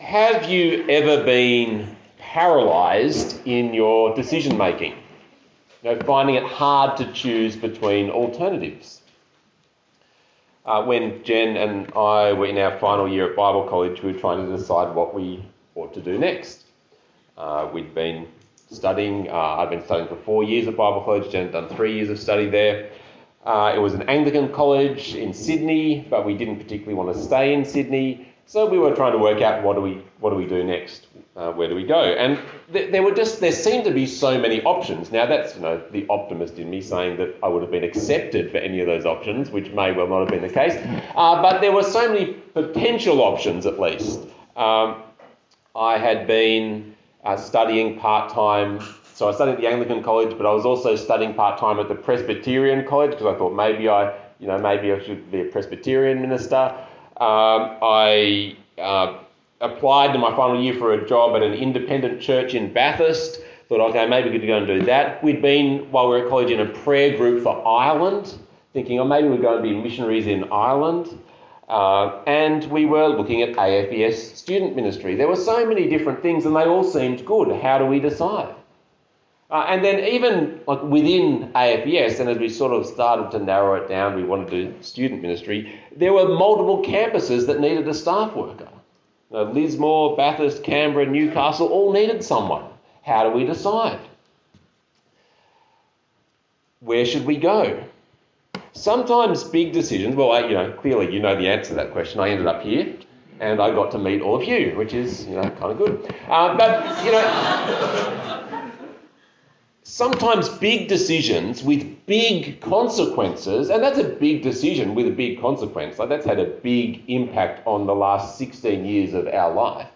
Passage: Proverbs 1:1-7 Talk Type: Bible Talk